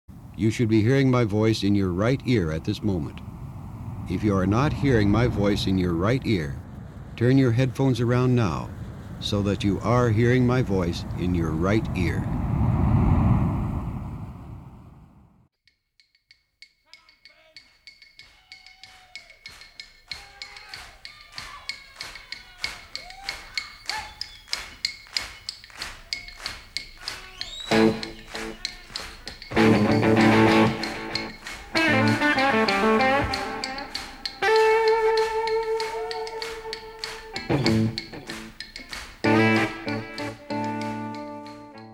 TestEarphone.mp3